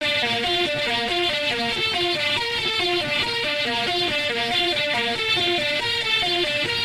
Organ Sounds » Distorted Organ Lead
描述：Organ Through a Metal Muff Distortion Pedal of a Hammond Sounder III Organ.
标签： Distorted lead Arpeggio Dirt Repeat
声道立体声